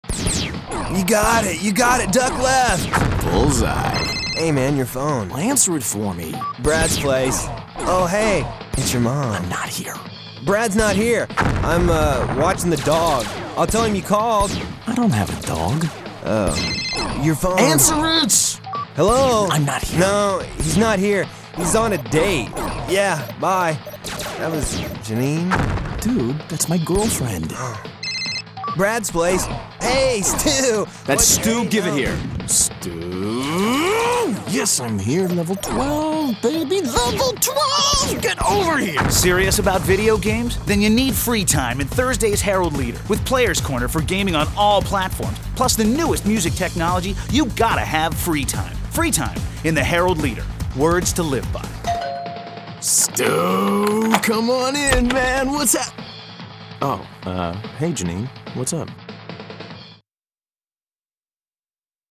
A radio spot heralding the Lexington Herald-Leader Weekender's new video game reviews section.